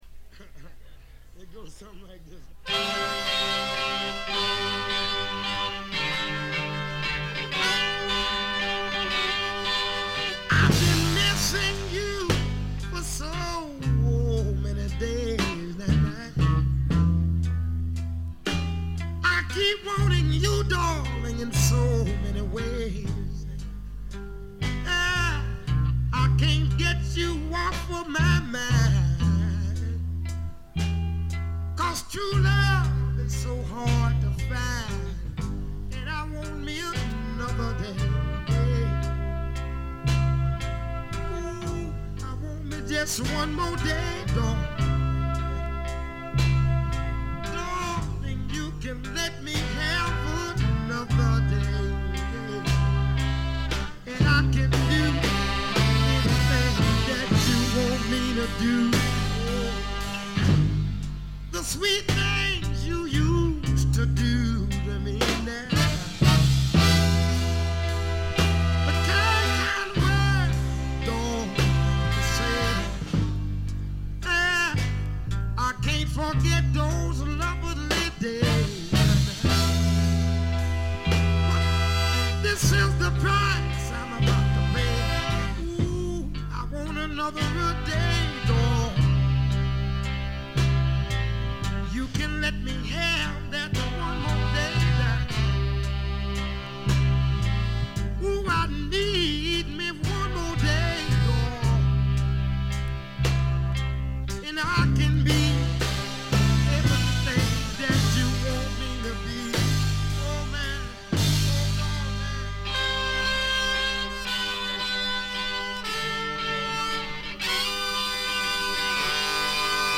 ほとんどノイズ感無し。
66年の録音で、国内ツアーメンバーによる演奏です。
全員一丸となってペース配分も考えずに疾走しきった感がしっかり伝わってくる素晴らしいライヴ音源です。
試聴曲は現品からの取り込み音源です。
Guitar
Bass
Drums
Tenor Saxophone
Trombone
Trumpet